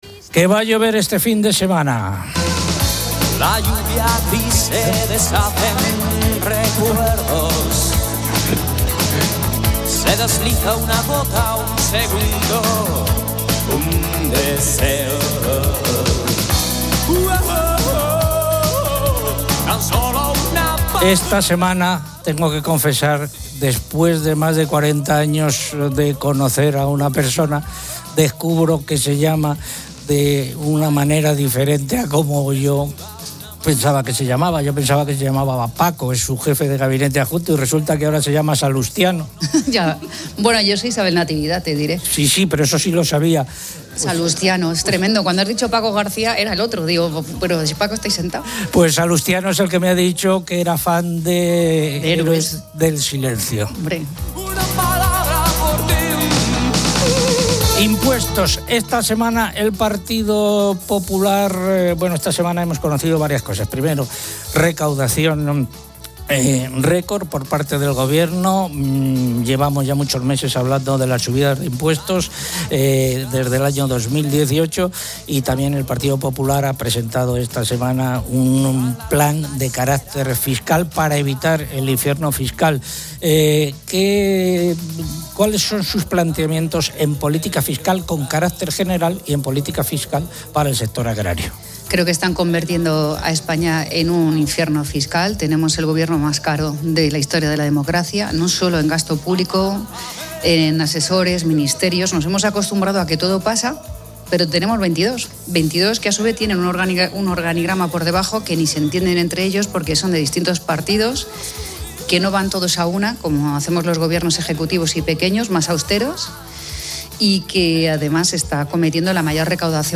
Isabel Díaz Ayuso, presidenta de la Comunidad de Madrid, en Agropopular